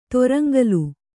♪ toraŋgalu